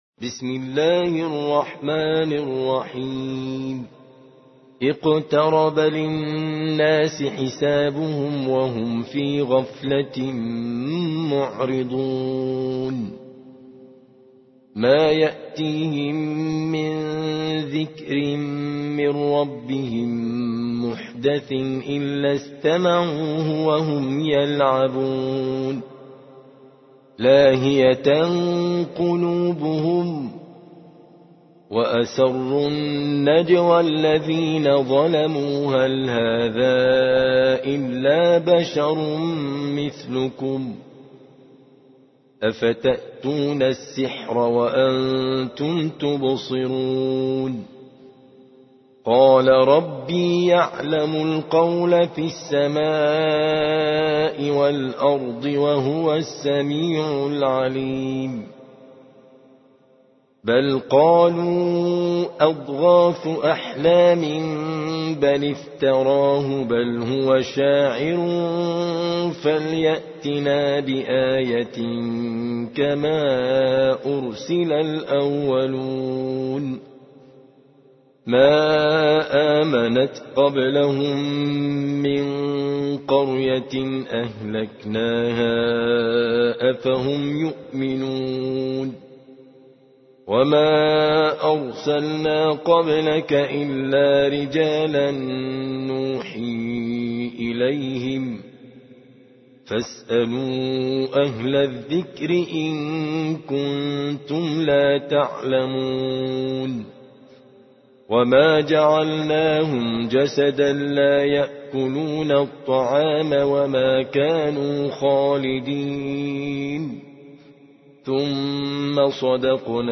21. سورة الأنبياء / القارئ